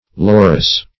Laurus \Lau"rus\, n. [L., laurel.]